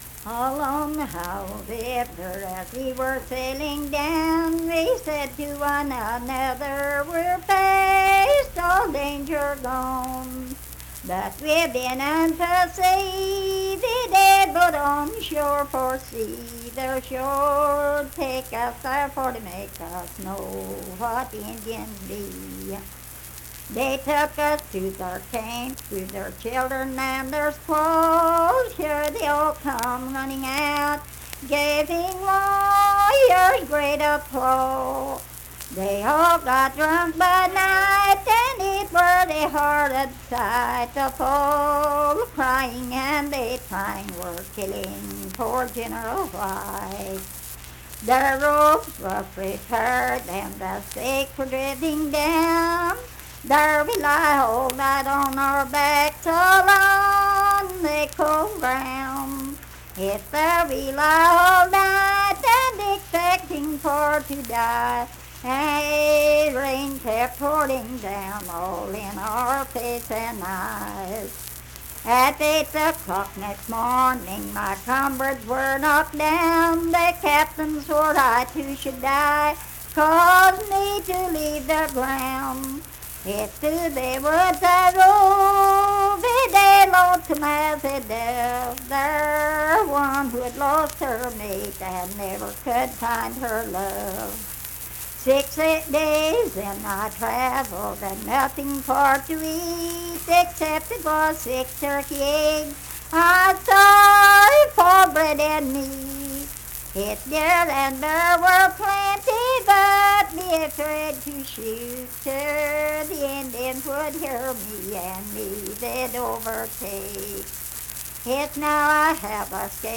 Unaccompanied vocal music performance
Verse-refrain 6(8).
Voice (sung)
Logan County (W. Va.)